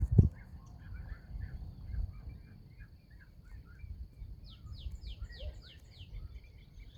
Varillero Congo (Chrysomus ruficapillus)
Nombre en inglés: Chestnut-capped Blackbird
Localización detallada: Cañada Fragosa
Condición: Silvestre
Certeza: Observada, Vocalización Grabada
Varillero-congo_1.mp3